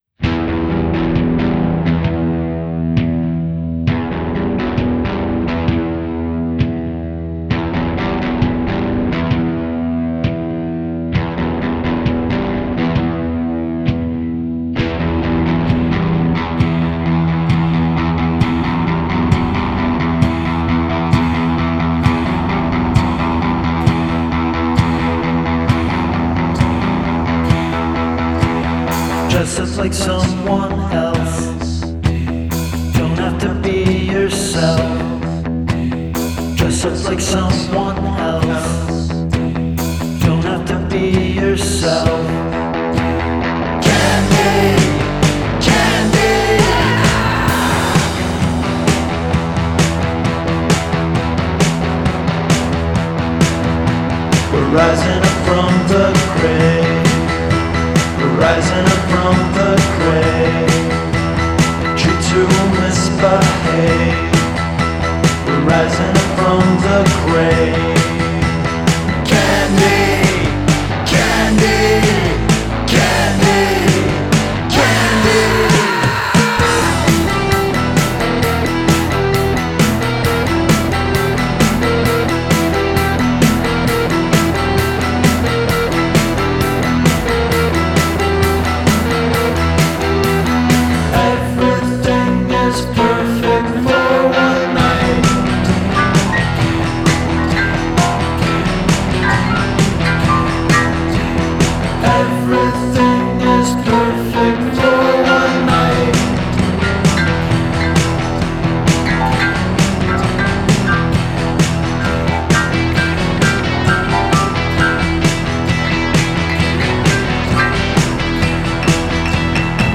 Garage-Punk Trio
bass, I play guitar